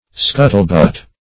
scuttlebutt \scut"tle*butt`\ (sk[u^]t"t'l*b[u^]t`), n.